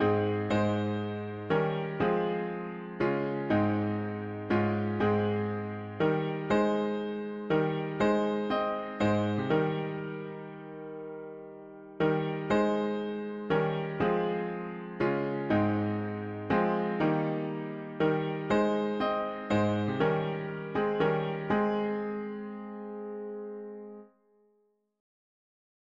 Up into thee, our living he… english christian 4part
Key: A-flat major Meter: CM